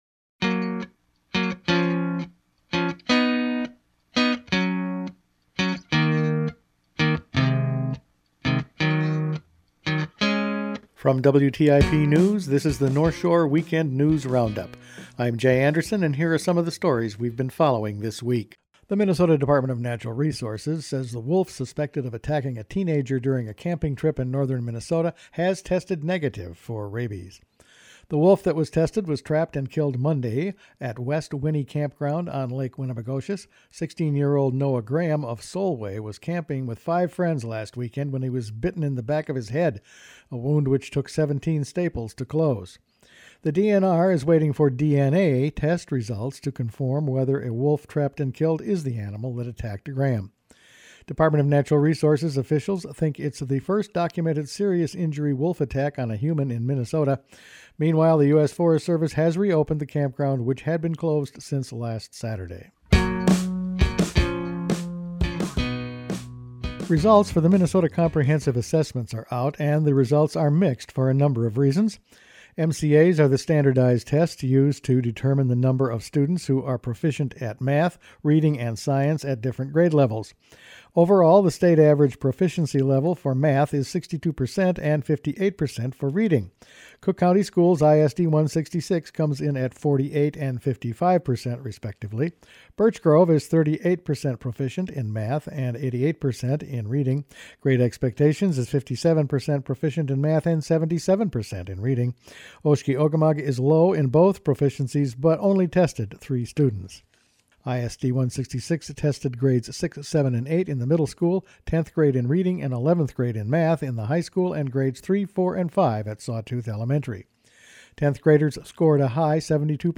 Weekend News Roundup for August 31
Each week the WTIP news staff puts together a roundup of the news over the past five days. Fires in the area, school test scores, wolves and campers and action on the Hwy 53 re-route…all in this week’s news.